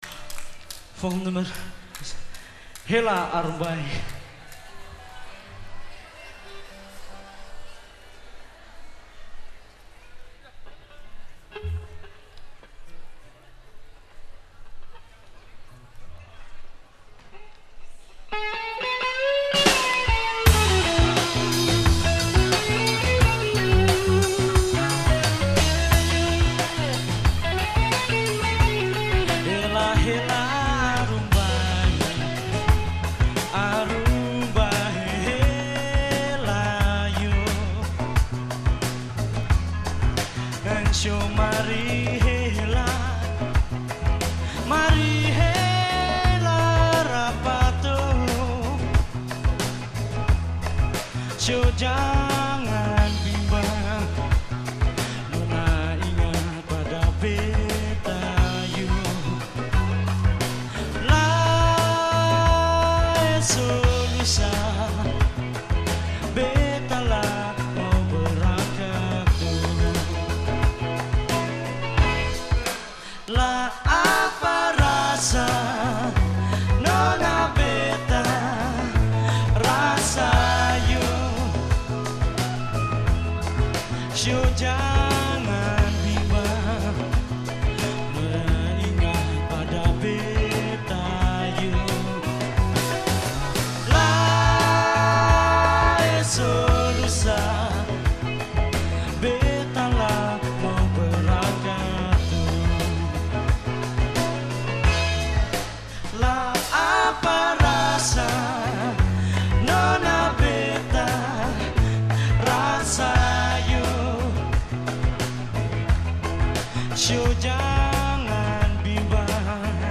Bas & Leadzang
Drums
Slaggitaar & Koorzang
Slag & Sologitaar
Sologitaar & Koorzang
Conga ‘s